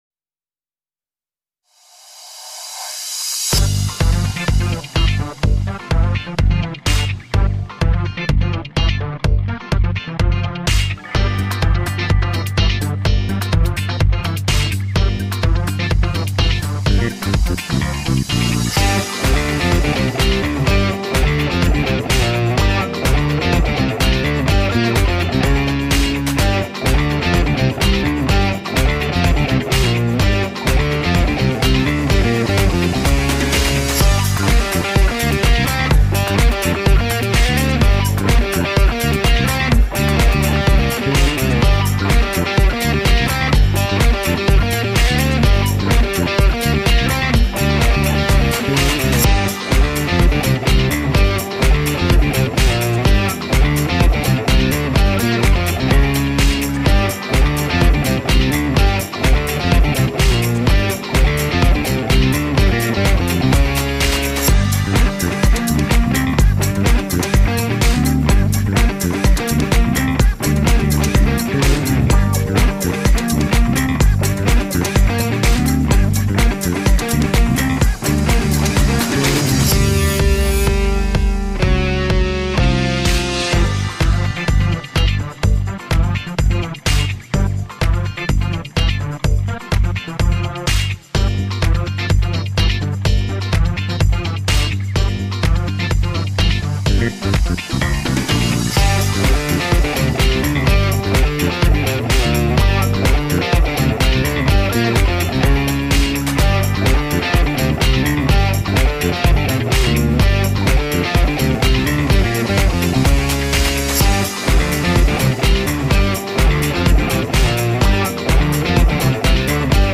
tema dizi müziği, heyecan neşeli eğlenceli fon müziği.